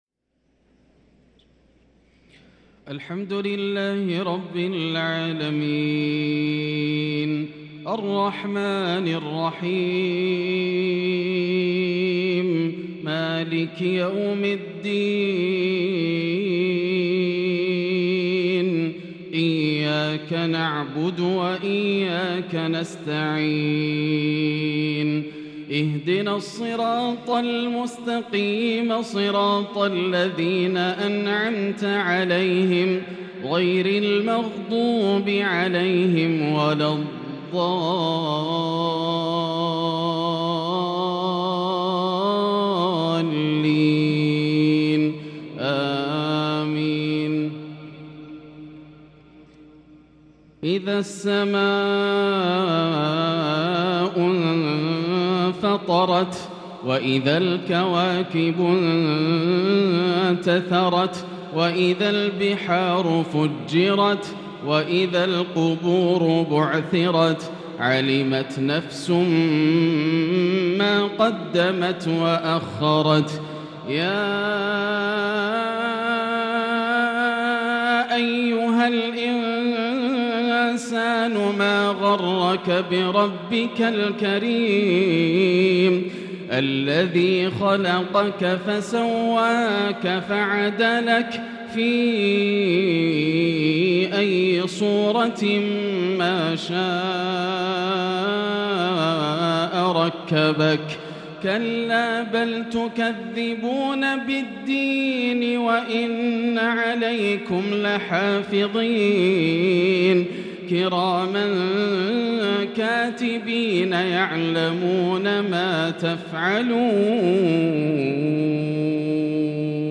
مغرب الجمعة 7-9-1443هـ سورة الإنفطار | maghrib prayer from surah Al-Infitar 8-4-2022 > 1443 🕋 > الفروض - تلاوات الحرمين